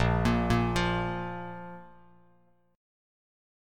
A#5 Chord
Listen to A#5 strummed